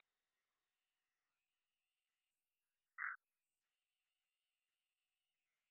Nahá, Chiapas
Rana arborícola de ojos rojos.
Agalychnis callidryas